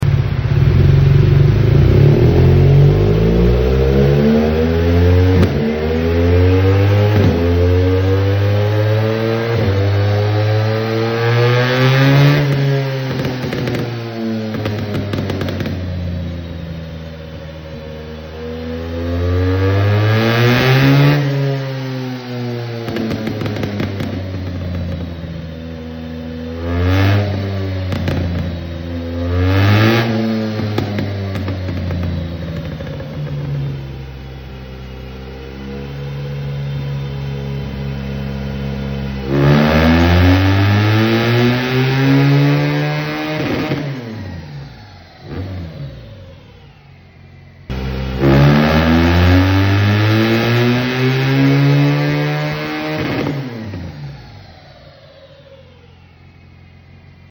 🇩🇪 BMW R1200GS custom Remapping sound effects free download
🇩🇪 BMW R1200GS custom Remapping after a full Exhaust upgrade -Titanium
Exhaust Light crackle tune requested by customer